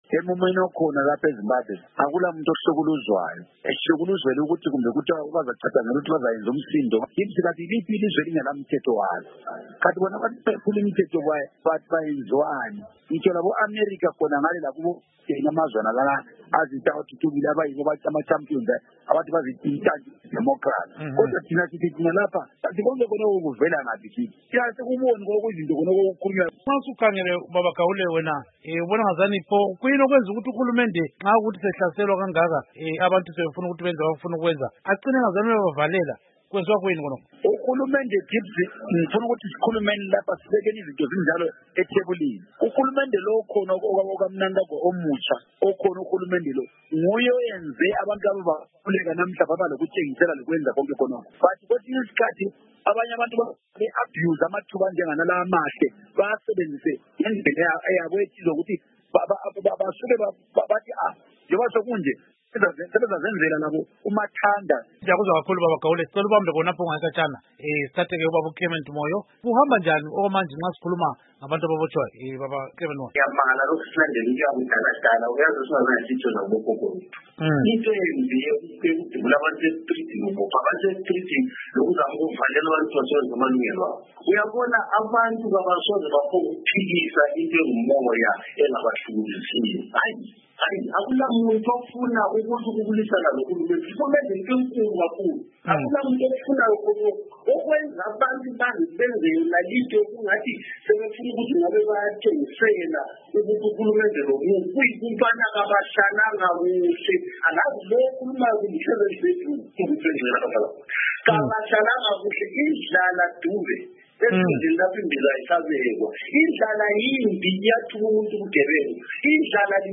Ukuhlaziya udaba lolu, sixoxe loMnu. Believe Gaule, owake wamela iTsholotsho edale leSenate, labalandeli bomsakazo weStudio 7.